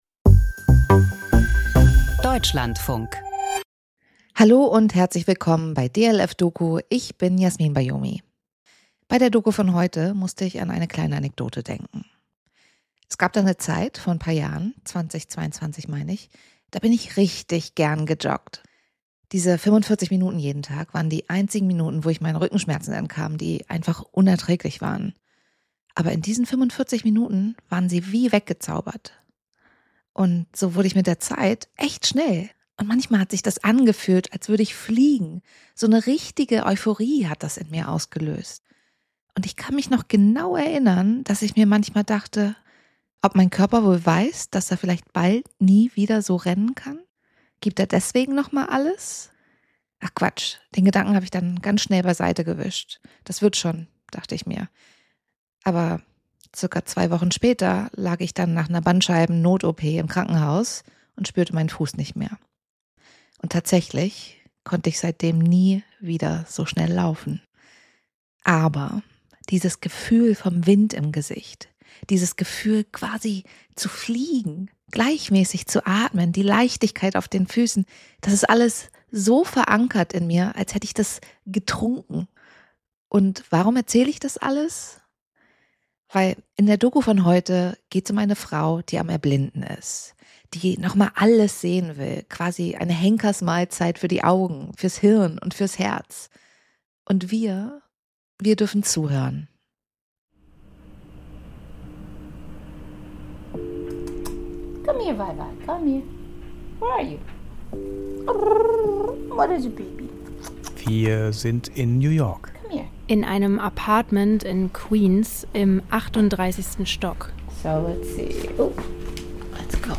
Feature Langsames Erblinden.